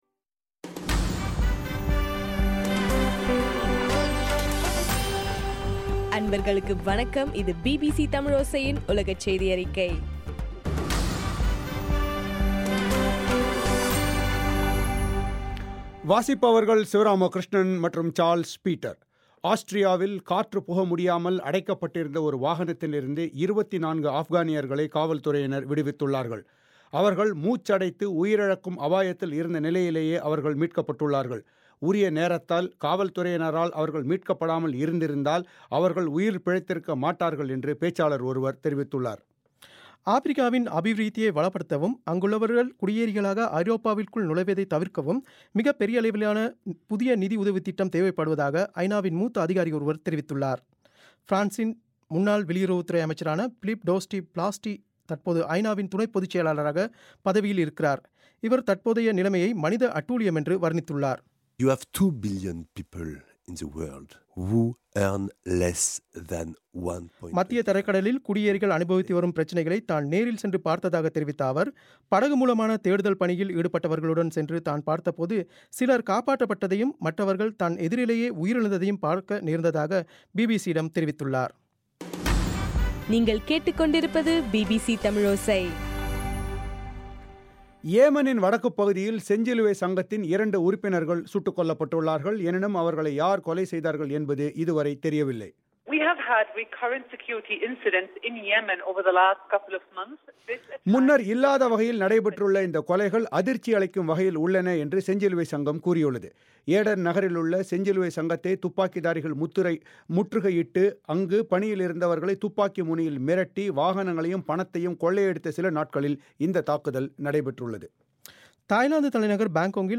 செப்டம்பர் 2, 2015 பிபிசி தமிழோசையின் உலகச் செய்திகள்